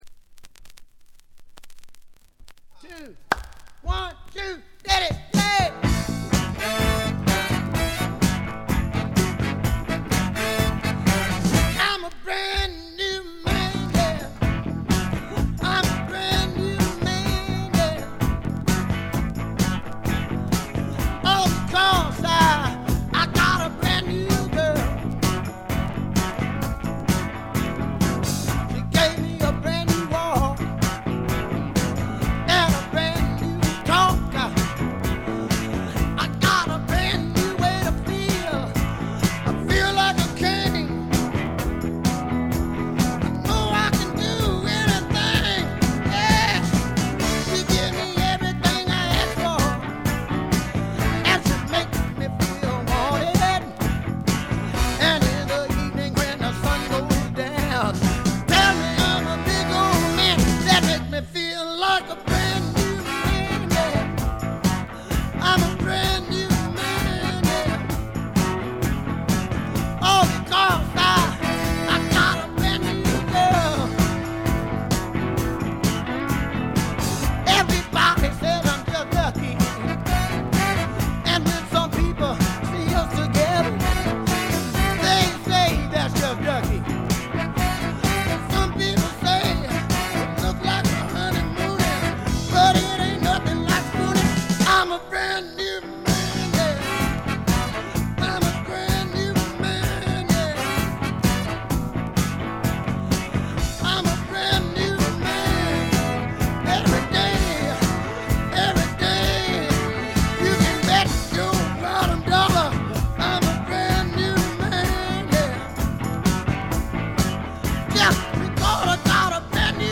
部分試聴ですが、わずかなノイズ感のみ。
予備知識がなければ100人が100人とも黒人シンガーと間違えてしまうだろうヴォーカルが、まず凄い！
試聴曲は現品からの取り込み音源です。